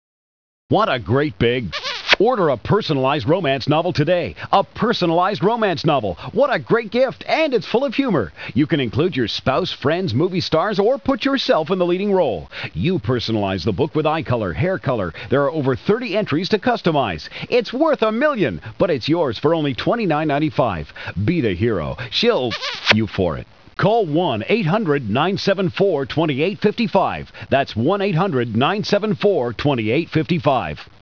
Radio and Podcast Commercials
Two KISSes - 30 second promo